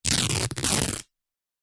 Звуки футболки
Звук рвущейся футболки